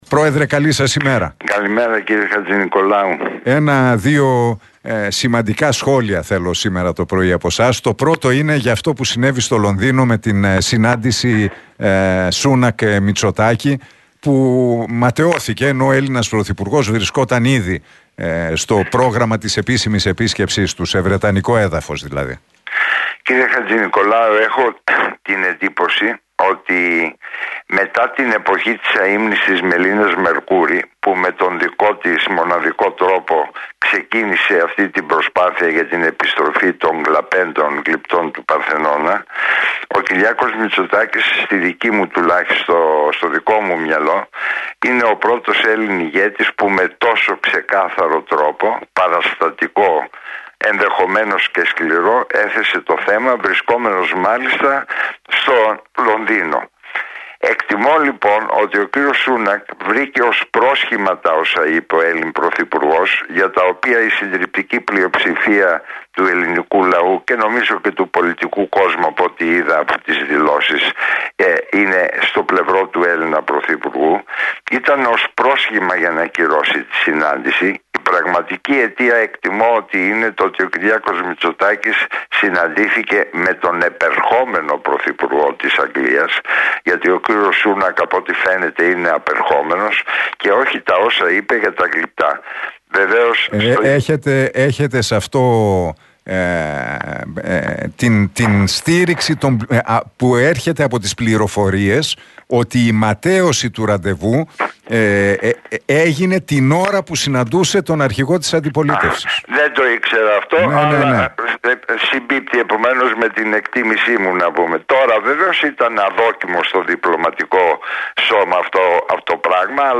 Ο Νικήτας Κακλαμάνης μίλησε στην εκπομπή του Νίκου Χατζηνικολάου στον Realfm 97,8.